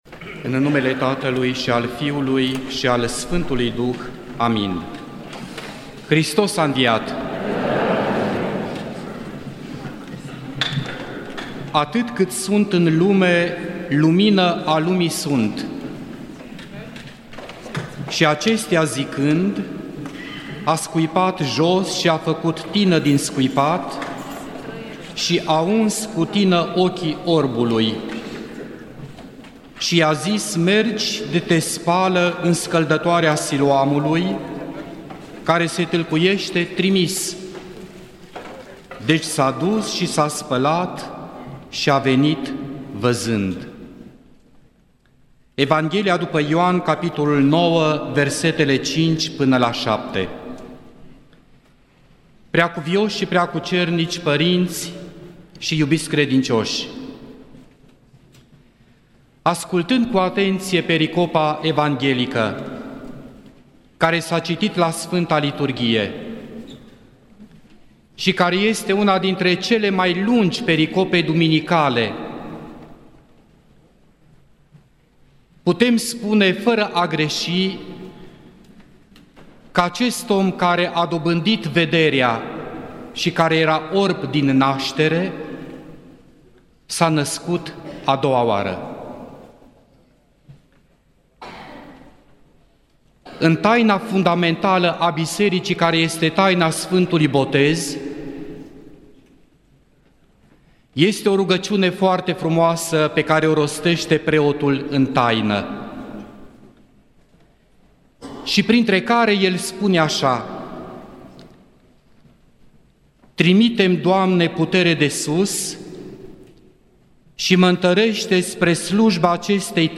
Predică la Duminica a 6-a după Paști (a Orbului din naștere)